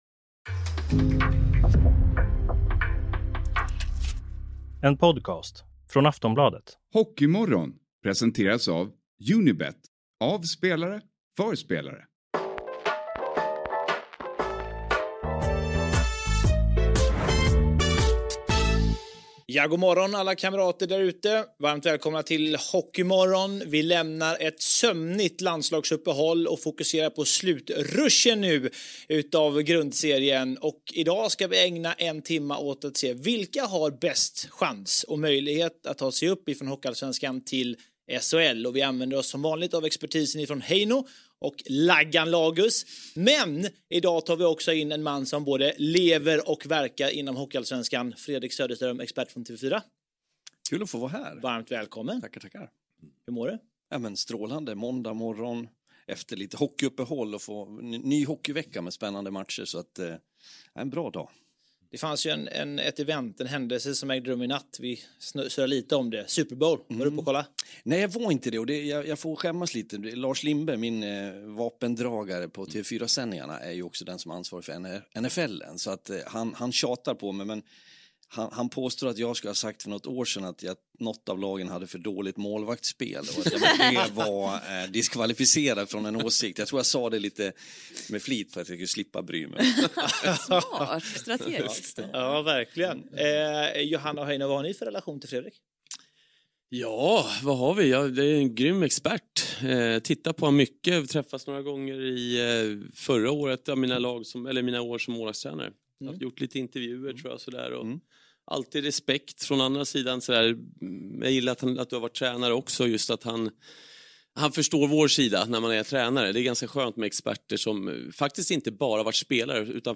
I studion
Gäst på länk